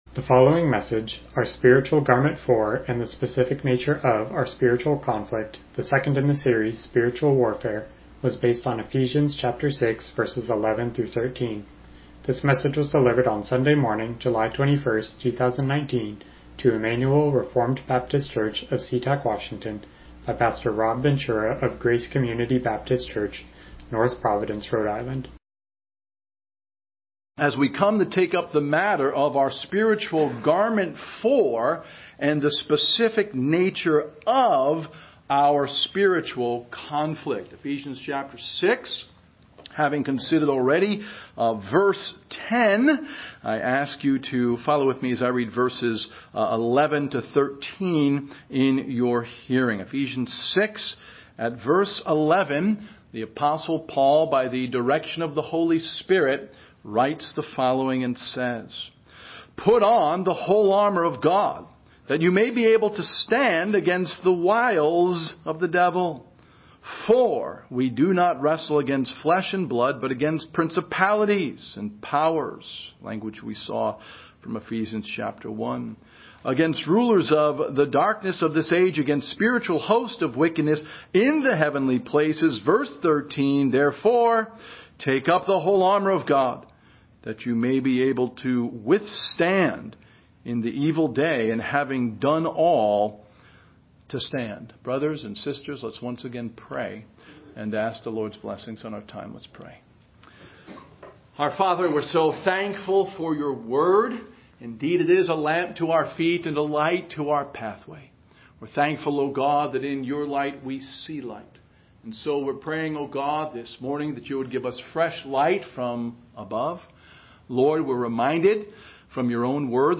Service Type: Morning Worship